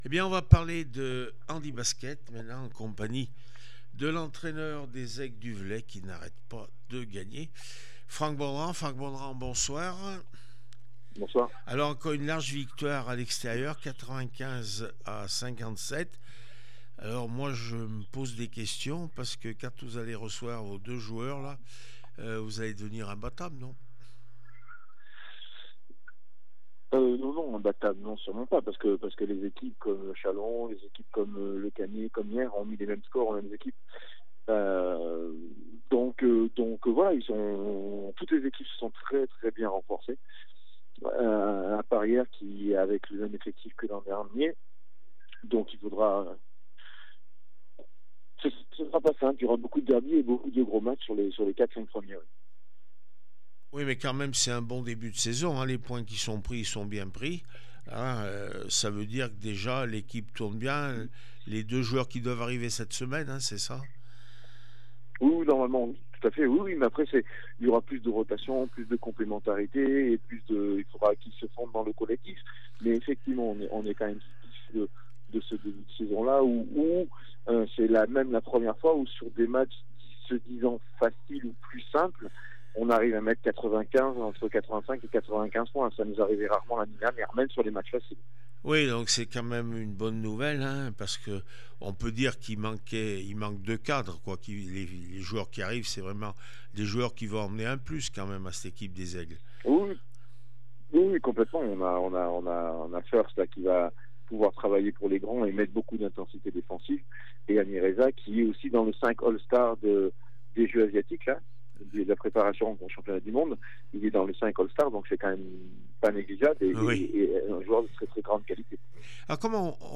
handi basket Elite Médicis Toulouse 57-95 les aigles du Velay réaction après match